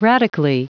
Prononciation du mot radically en anglais (fichier audio)
Prononciation du mot : radically